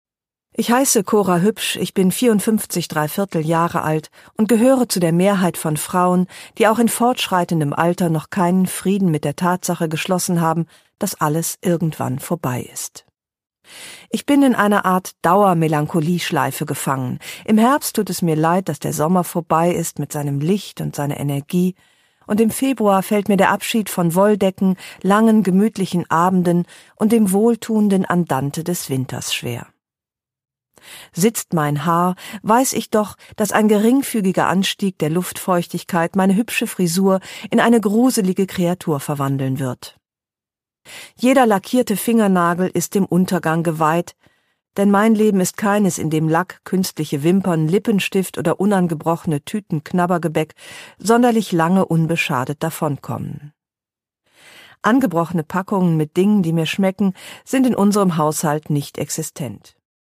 Produkttyp: Hörbuch-Download
Gelesen von: Ildikó von Kürthy